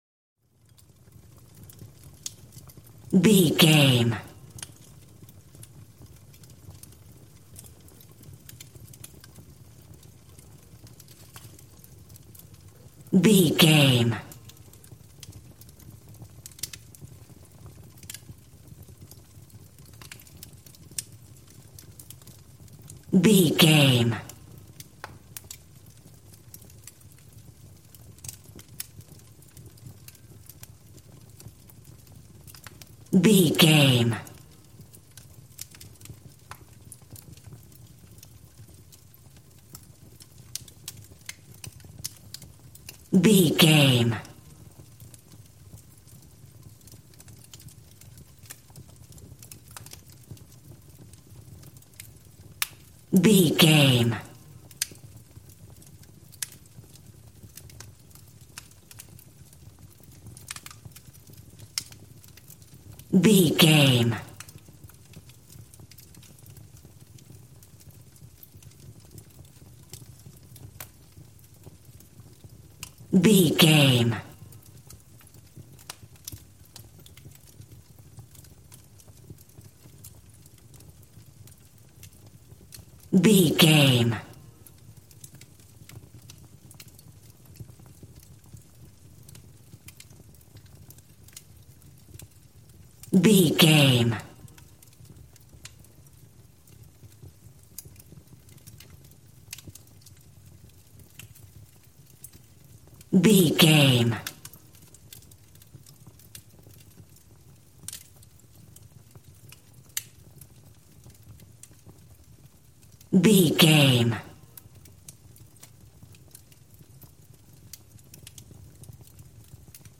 Fireplace with crackle
Sound Effects
fireplace